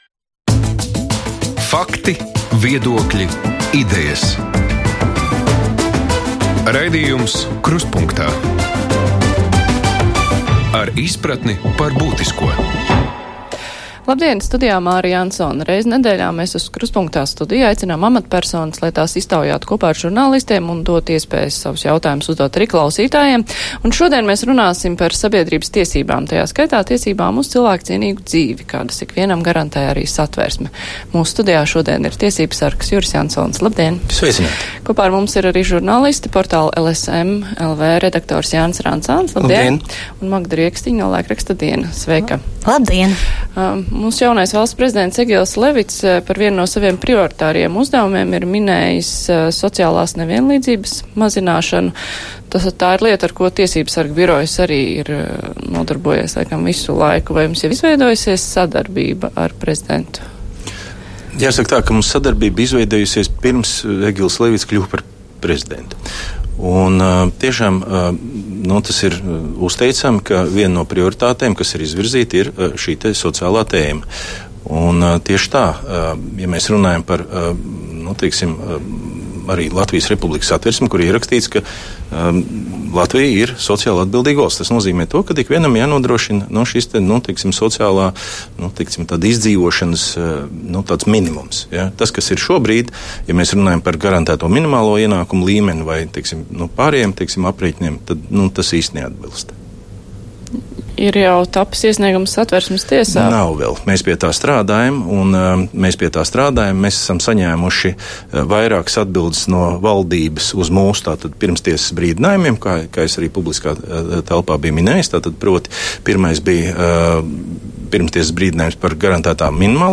Reizi nedēļā uz studiju aicinām amatpersonas, lai tās iztaujātu kopā ar žurnālistiem un dotu iespēju savus jautājumus uzdot arī klausītājiem. Šodien saruna par sabiedrības tiesībām, tajā skaitā tiesībām uz cilvēka cienīgu dzīvi, kādas ikvienam garantē arī Satversme. Mūsu studijā šodien būs tiesībsargs Juris Jansons.